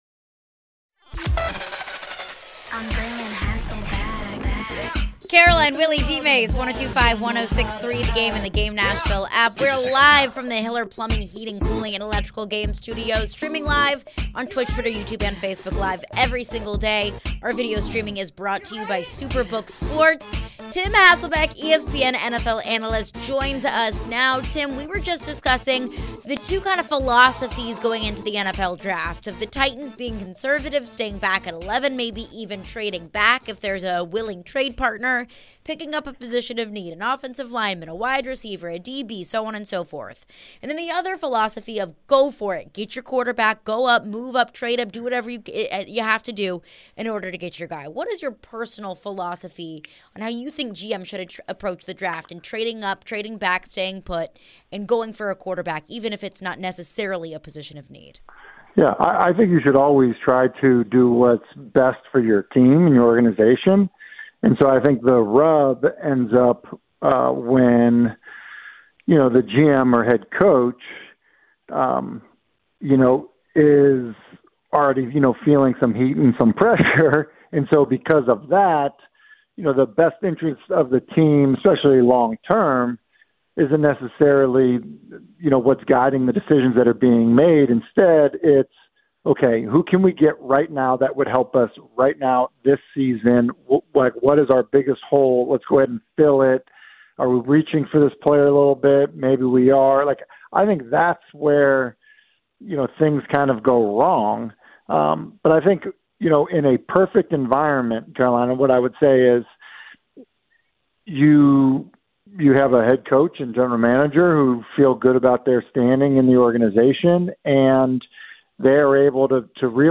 Tim Hasselbeck Interview (4-18-23)
ESPN NFL Analyst Tim Hasselbeck joins for his weekly visit & weighs in on the latest in the NFL.